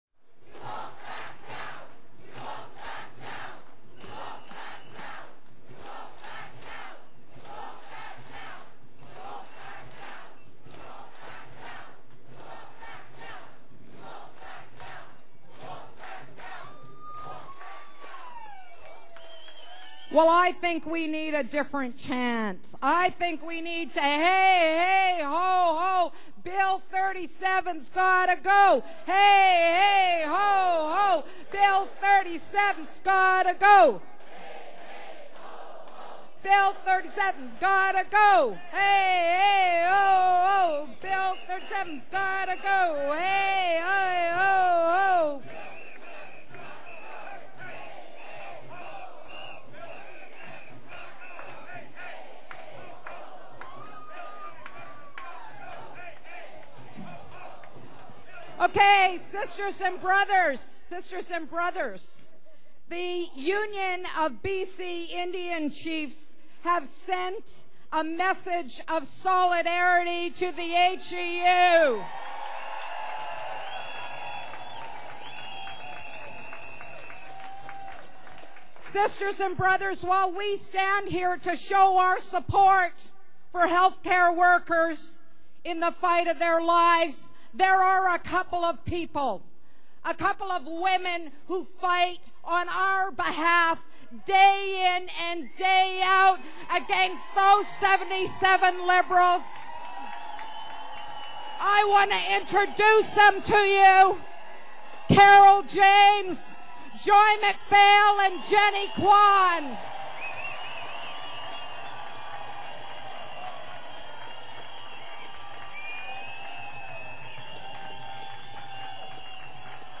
MAYDAY RALLY: "Voices for Justice" at the Vancouver Art Gallery, culmination of Mayday march.
Mayday rally - Solidarity messages and introductions RT: 4:02